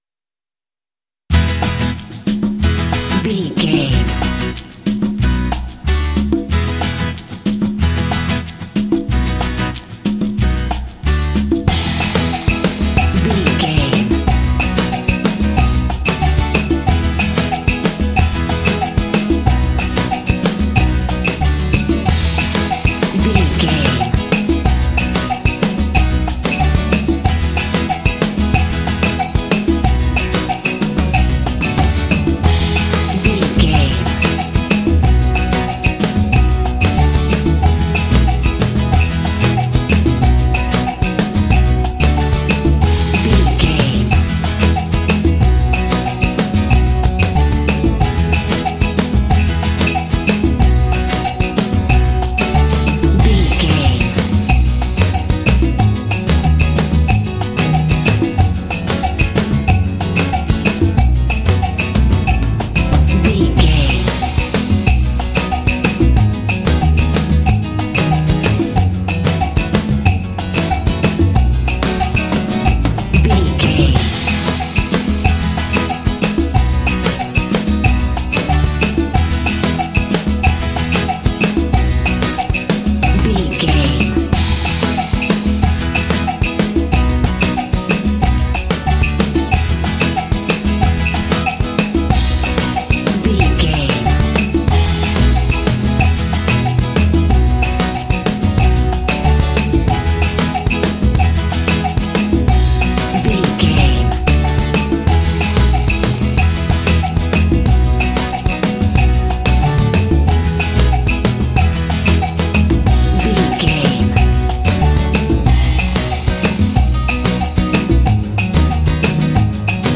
Uplifting
Ionian/Major
festive
lively
bouncy
energetic
percussion
acoustic guitar
drums
jazz